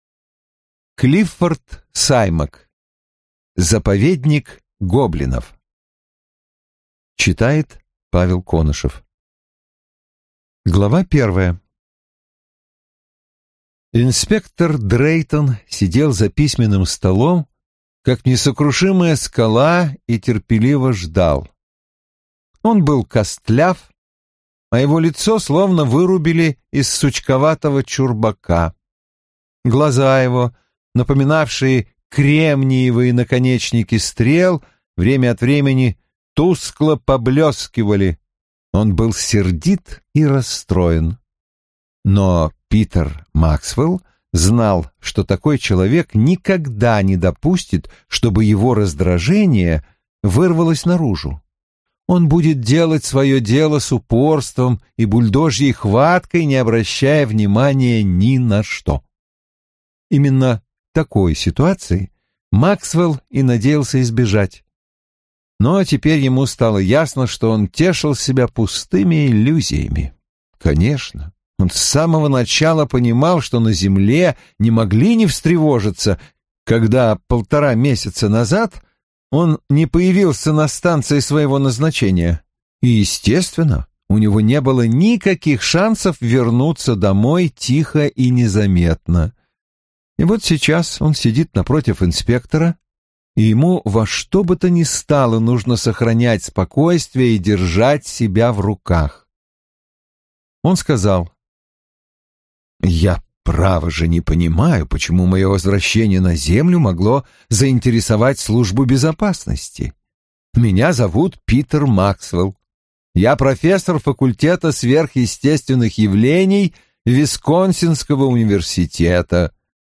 Аудиокнига Заповедник гоблинов - купить, скачать и слушать онлайн | КнигоПоиск